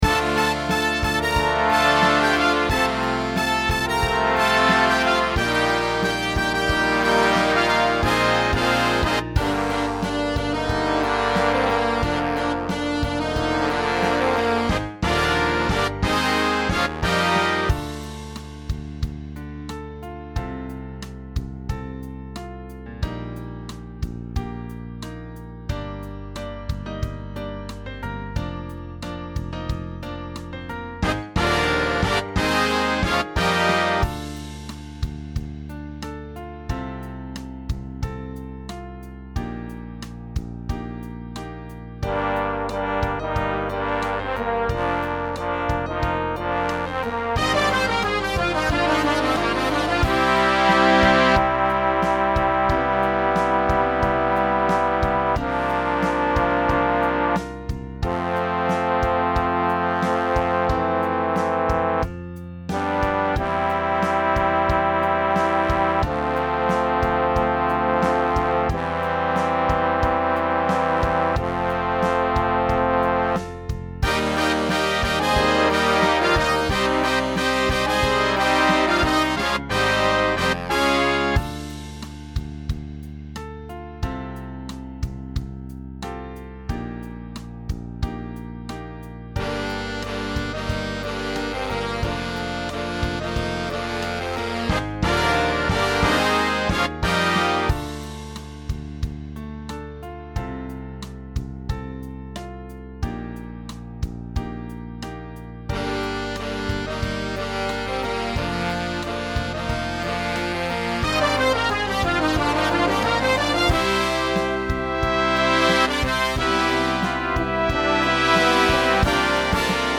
Spectacular Alto sax feature
Big Band Instrumental